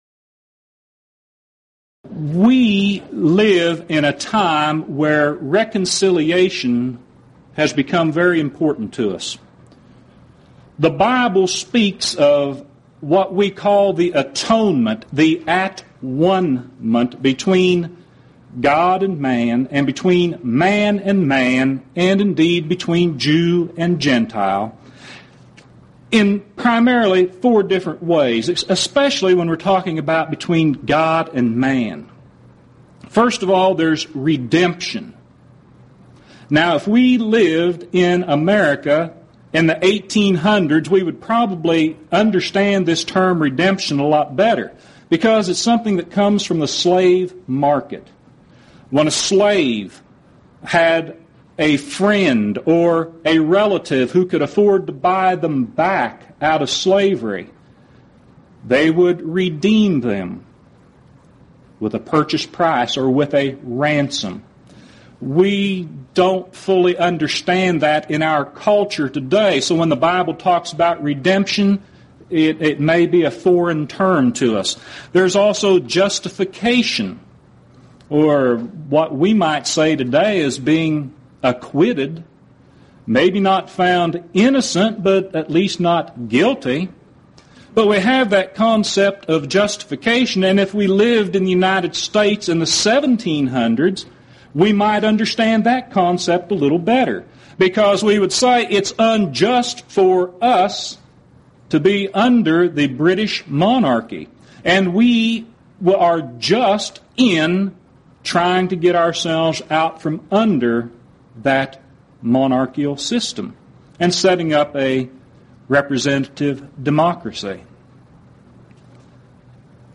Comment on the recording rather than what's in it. Event: 2nd Annual Lubbock Lectures